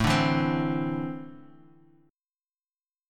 A4-3 chord